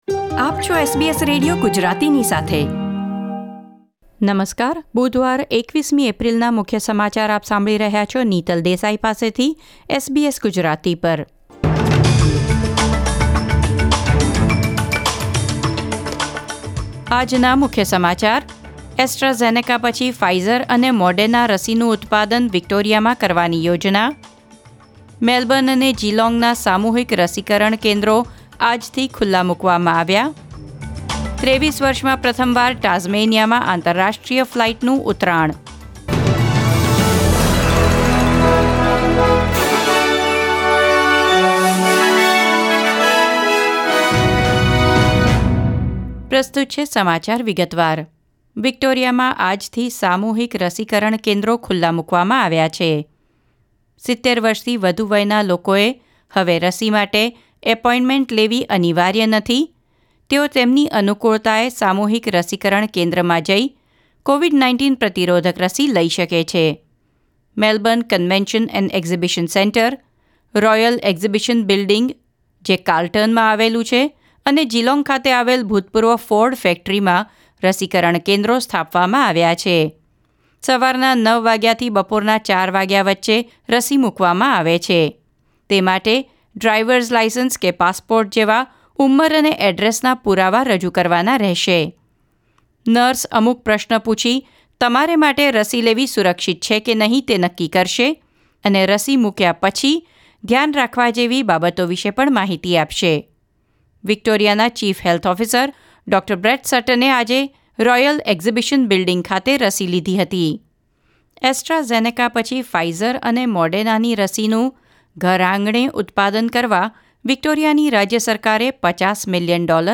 SBS Gujarati News Bulletin 21 April 2021